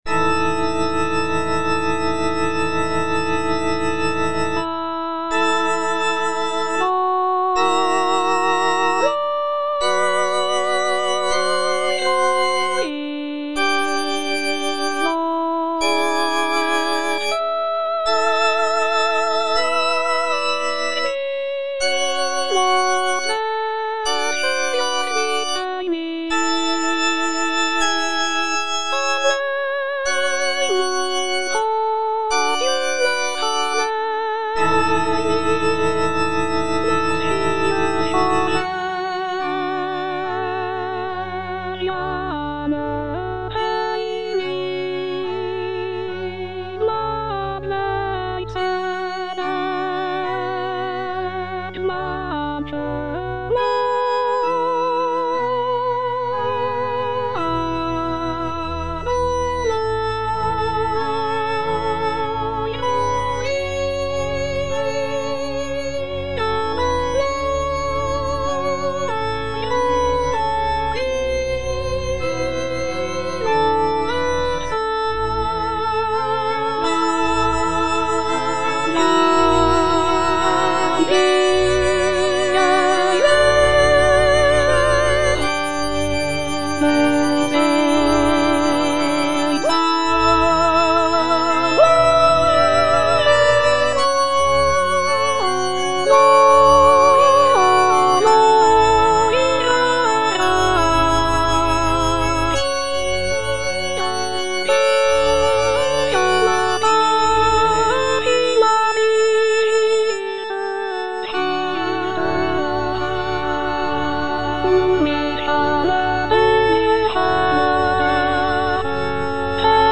(soprano II) (Emphasised voice and other voices) Ads stop